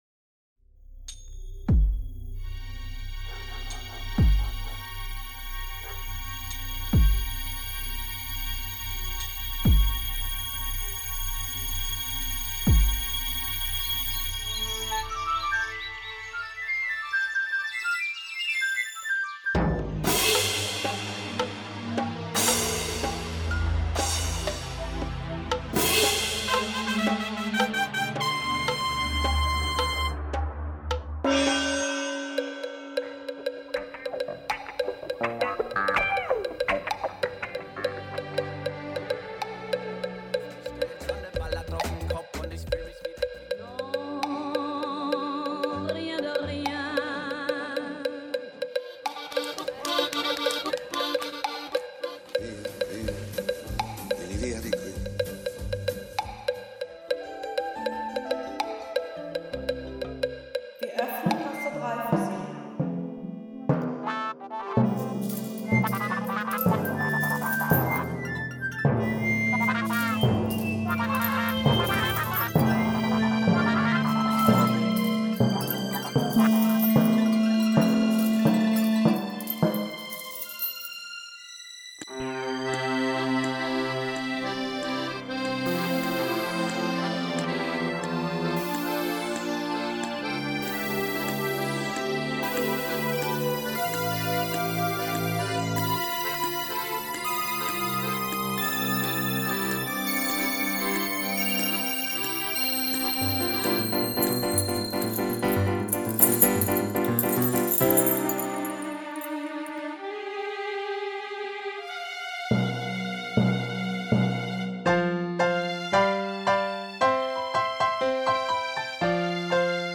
Für uns könnte der Weg zum Atelier so klingen (von der Haltestelle Raiffeisenstraße in Köln-Poll bis in unser Atelier 3.86 im Quartier am Hafen – inkl. des obligatorischen Einkaufs beim Aldi nebenan):